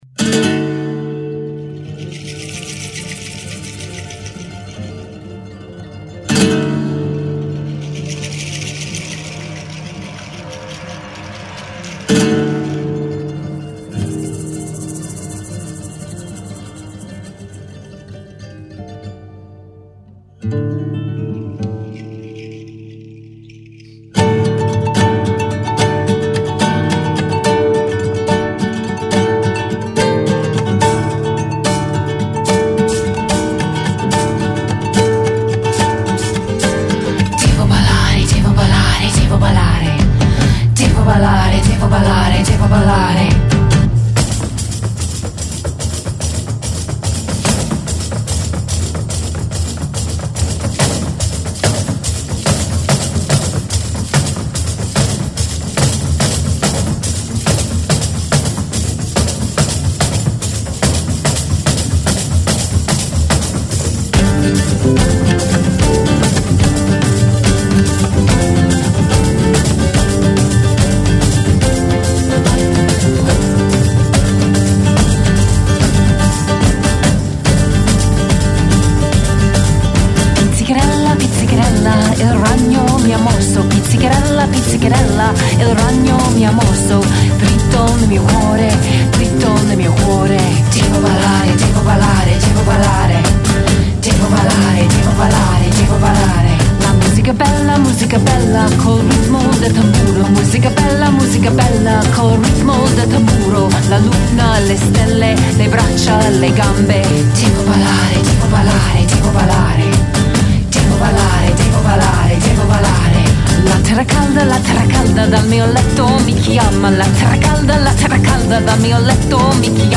vocals, tamburello
guitar
electric bass
drums and percussion